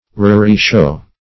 Raree-show \Rar"ee-show`\ (r[^a]r"[-e]-sh[=o]`), n. [Contr. fr.